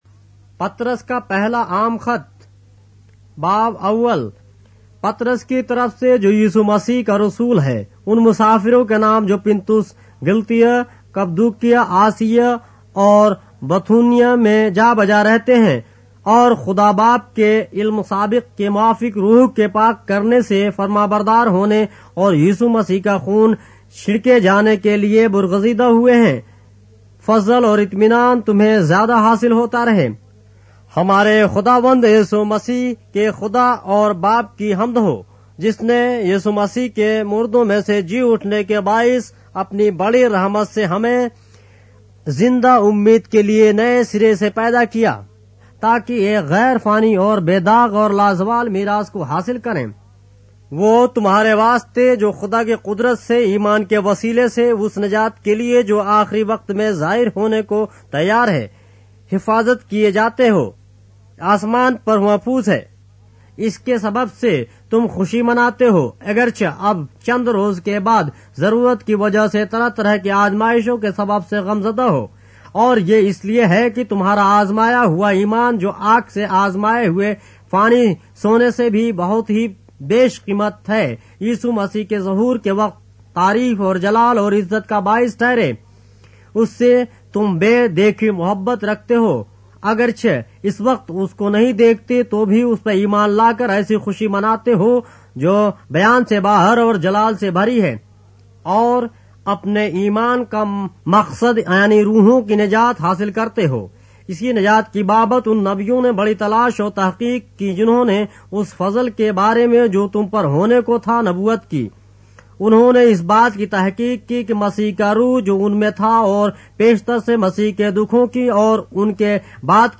اردو بائبل کے باب - آڈیو روایت کے ساتھ - 1 Peter, chapter 1 of the Holy Bible in Urdu